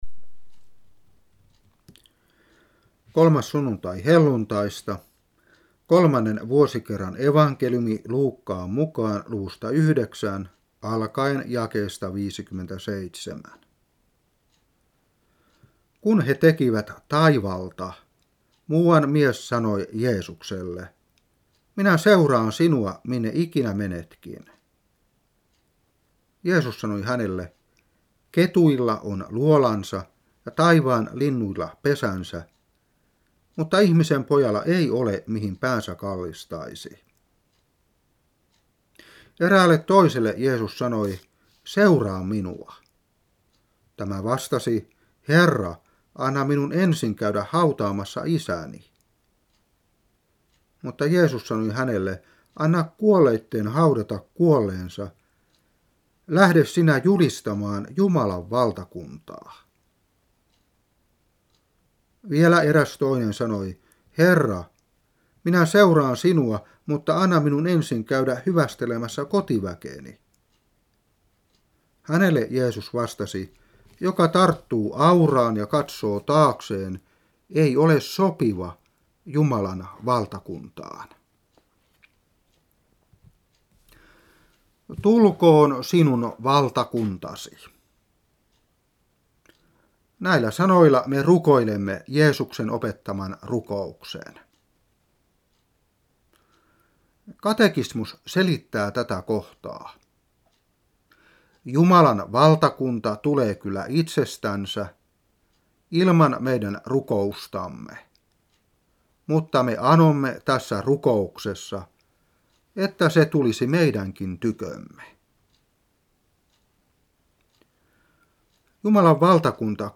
Saarna 1998-6.